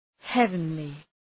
Προφορά
{‘hevənlı}